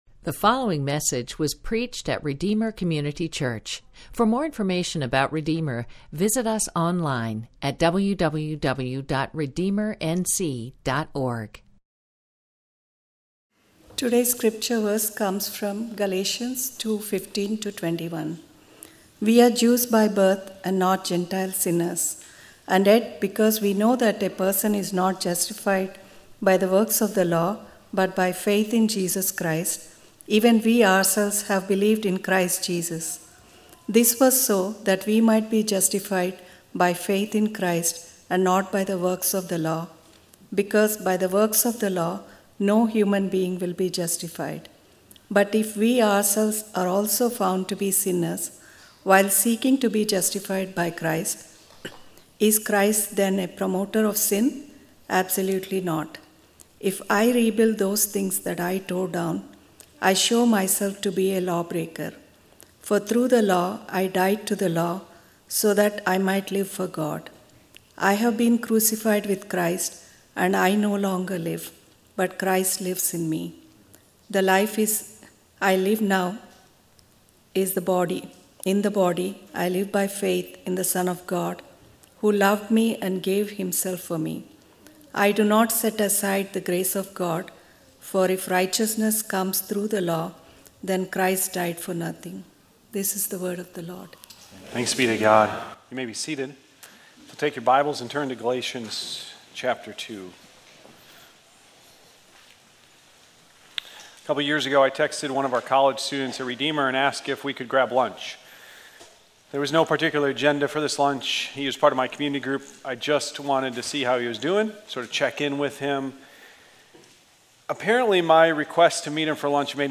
From Series: "Stand-Alone Sermons"